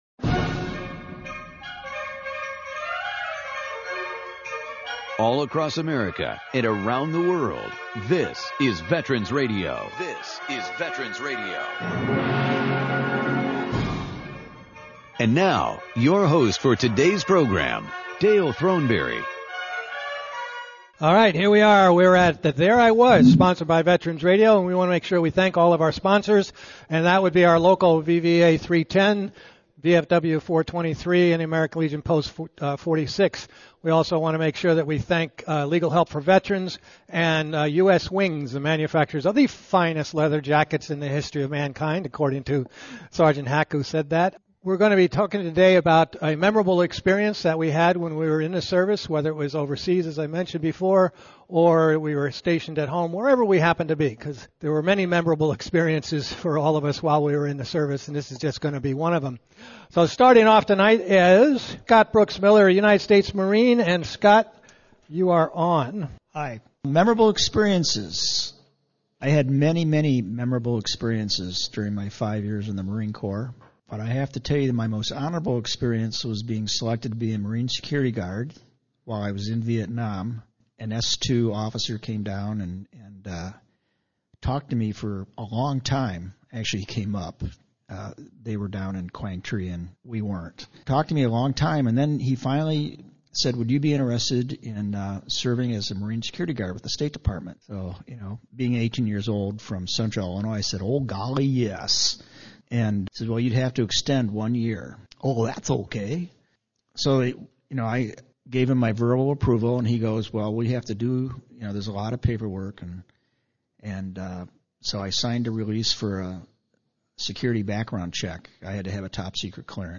“There I Was…” is an open mic program where veterans tell their stories. This week’s focus is Memorable Moments.